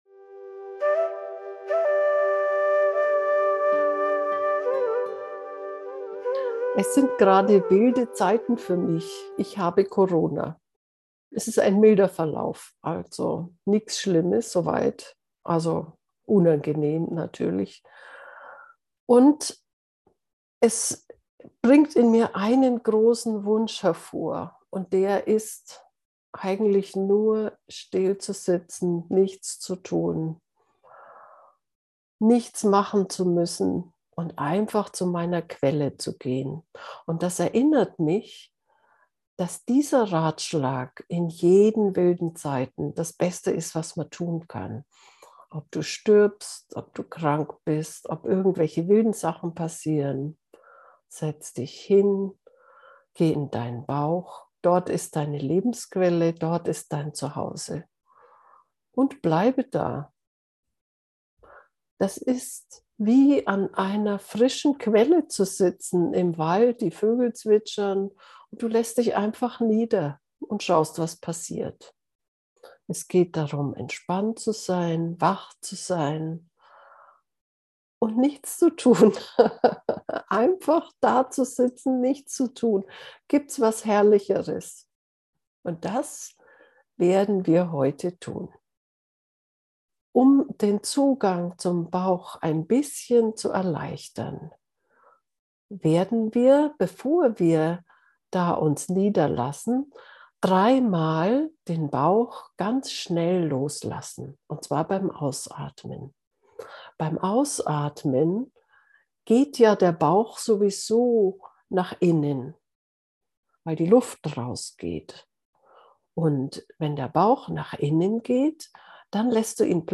Und habe nur einen Wunsch: still in meiner Lebensquelle im Bauch zu sitzen. Nichts tun und das innere Zentrum im Bauch genießen. Eine geführte Meditation.